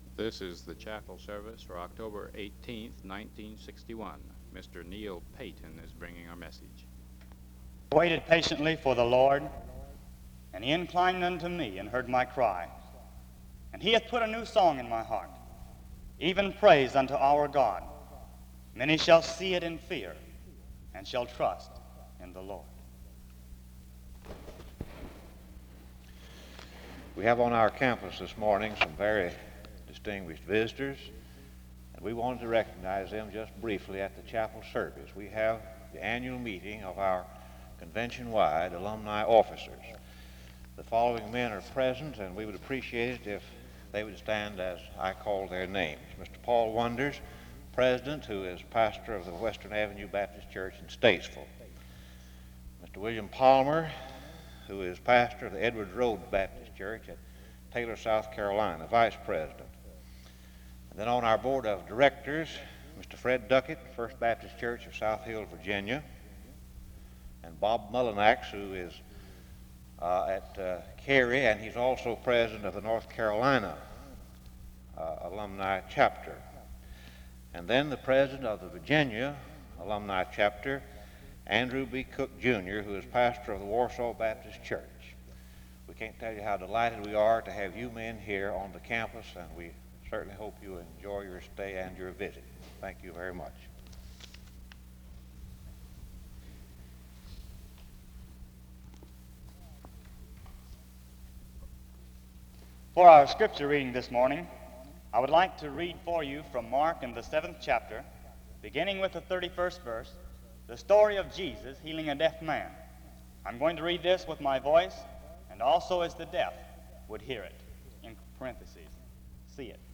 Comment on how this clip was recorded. This service was organized by the Student Coordinating Council. The service starts with a scripture reading from 0:00-0:23. A moment to welcome all those who are in the service is given from 0:27-1:33.